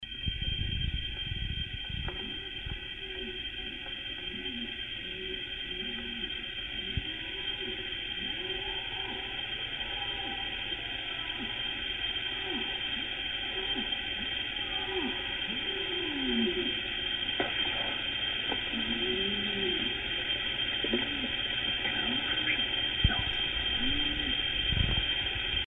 at one point they were so close to us. i would say less than 75 yards. the sounds they make at that distance seem right on top of you. we could feel our chests vibrating.
TIME AND CONDITIONS: off and on from11:00-1:00 lots of moonlight calm and quiet almost no wind perfect for listening
ENVIRONMENT: pine forest and bushes and brush some farmers live in the area and consequently there are also cow pastures and fields.
Public feedback about these sounds is pointing us in the direction of at least some of these calls being atypical cattle calls.
- These sounds were recorded along a two-mile stretch of road.
- The auditory observation lasted until the animals moved far off in the distance heading southeast, with sounds fading as they left.
We also amplified the sounds so that they could be heard better (this was necessary due to the nature of the digital camera microphone and the way it picks up distant sounds).